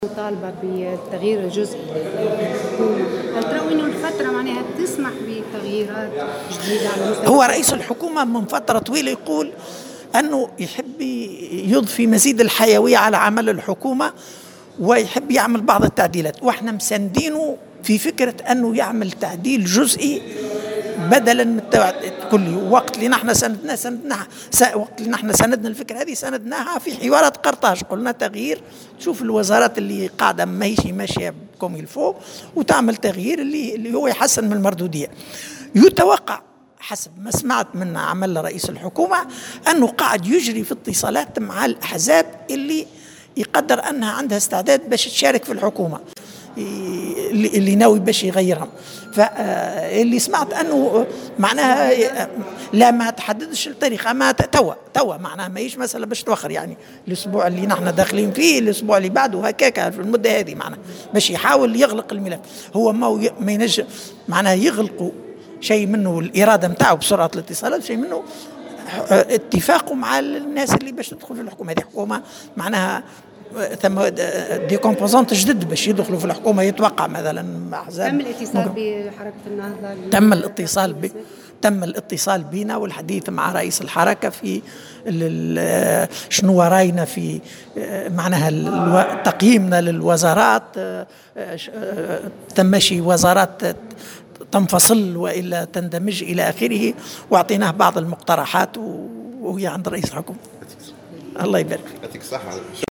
أكد نائب رئيس حركة النهضة، علي العريّض، اليوم الأحد في اختتام الندوة السنوية الثانية للحركة بالحمامات، أن التغيير الجزئي للحكومة سيكون في غضون الايام القليلة القادمة.